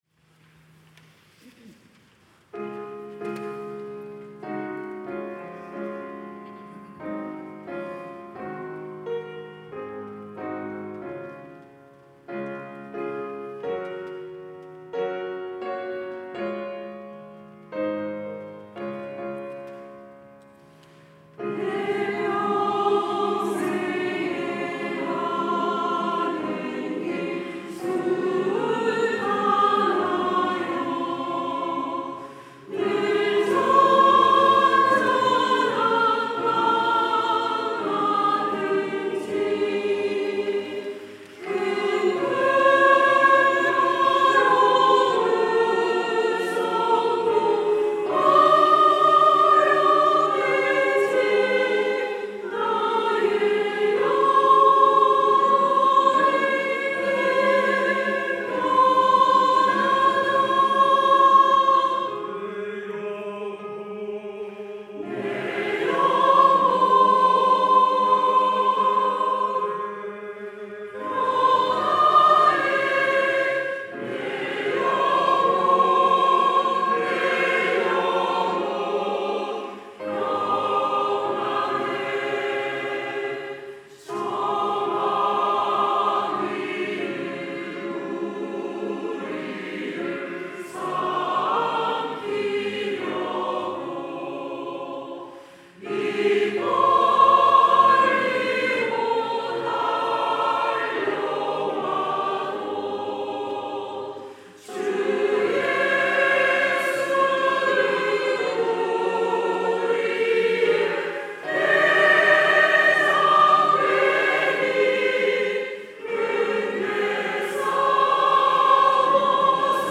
천안중앙교회
찬양대 가브리엘